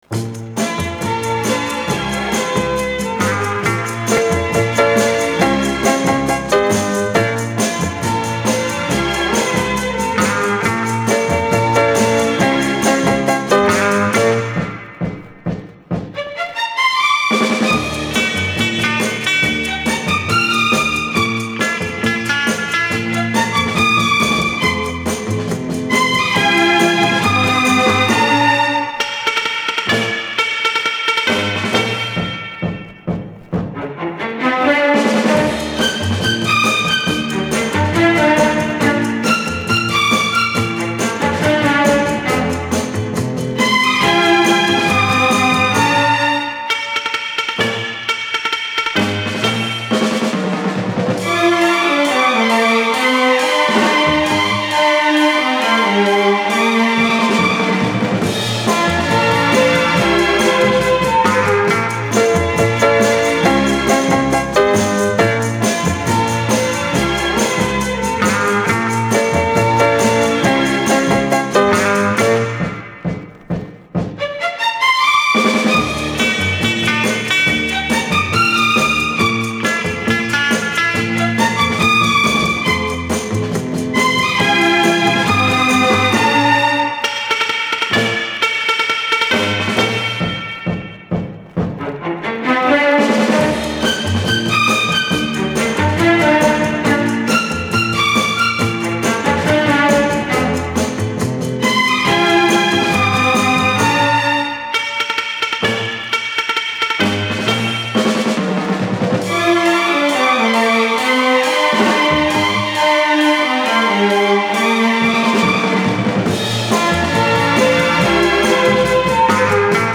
Французский флейтист, пианист и композитор.